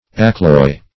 Search Result for " accloy" : The Collaborative International Dictionary of English v.0.48: Accloy \Ac*cloy"\ ([a^]k*kloi"), v. t. [OF. encloyer, encloer, F. enclouer, to drive in a nail, fr. L. in + clavus nail.]
accloy.mp3